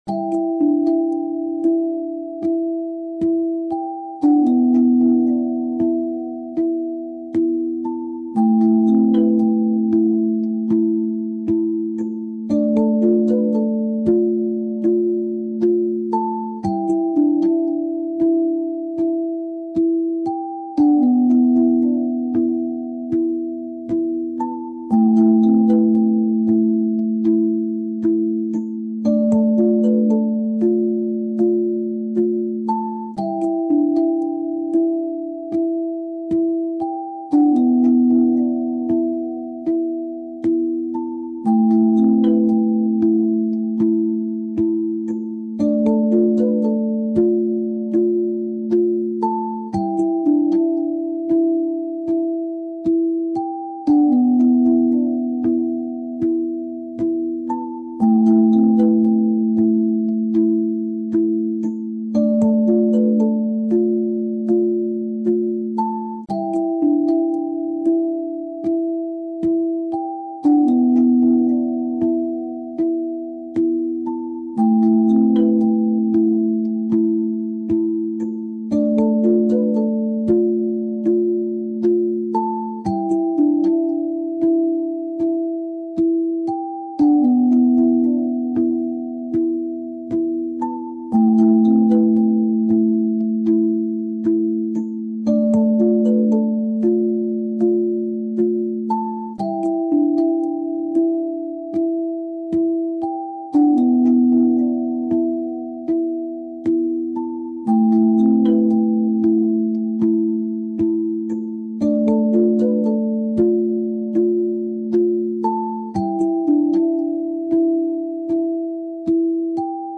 Einschlafmusik für Kinder!
Eigenkomposition & eingespielt mit KI-Unterstützung.